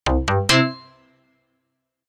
Electronica 2 System sound (Download MP3, 54KB, 0:02) Synth sound
Very Short Tones Short Tones Medium Tones Long Tones SND Pad Player Terms of Use